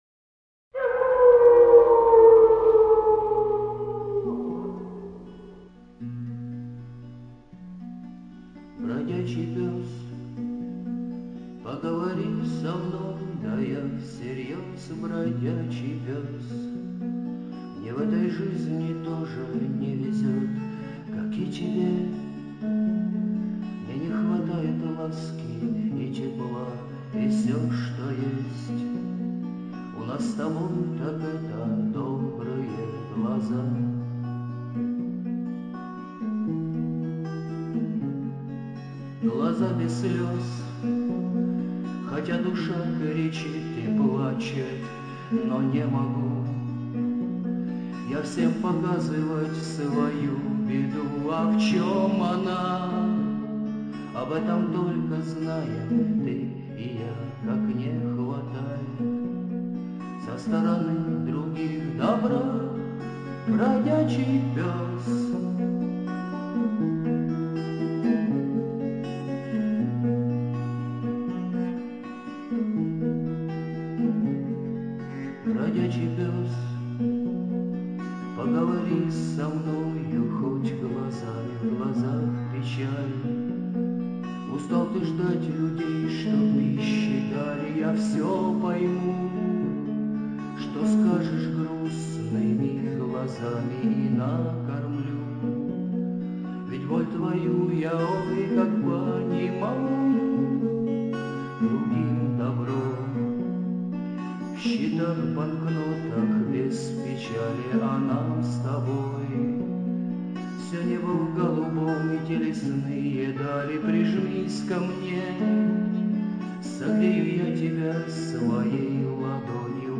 исполнение под гитару
Авторская песня Песня под гитару